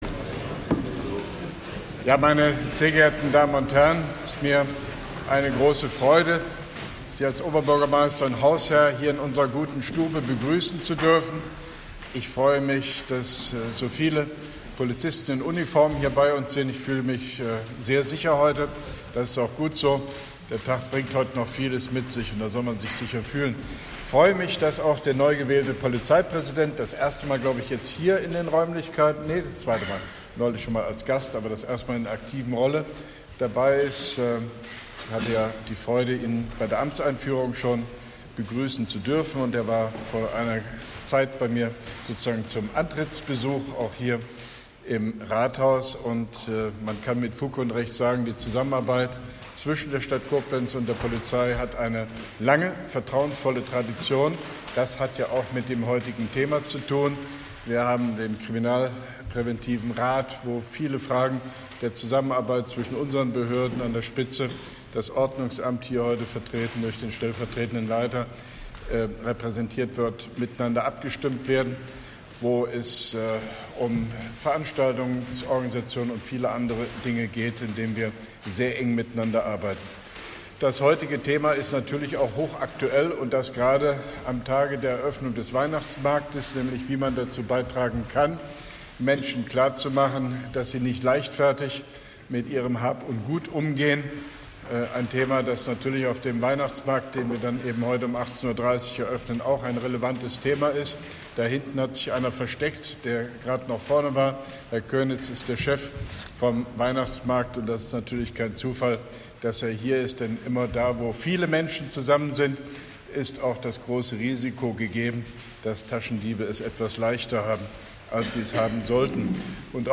Statement von OB Hofmann-Göttig beim Start der Präventionskampagne “Taschendiebstahl”, Koblenz 24.11.2017